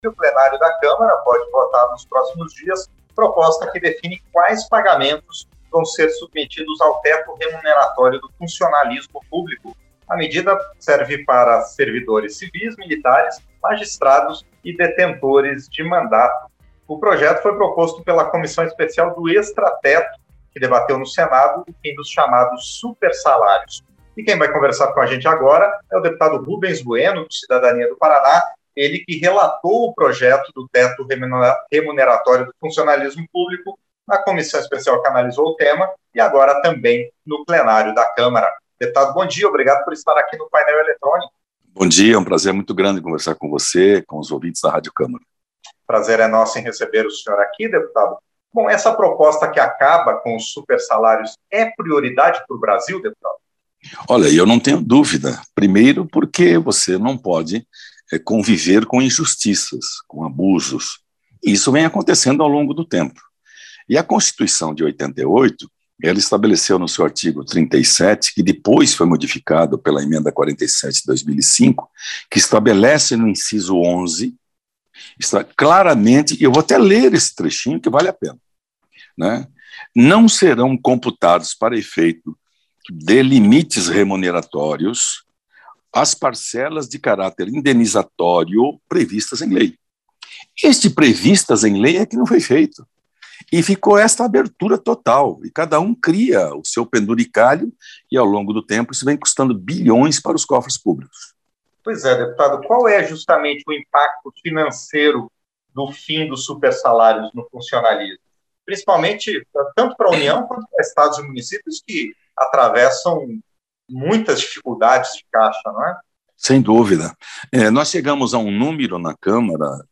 • Entrevista - Dep. Rubens Bueno (Cidadania-PR)
Programa ao vivo com reportagens, entrevistas sobre temas relacionados à Câmara dos Deputados, e o que vai ser destaque durante a semana.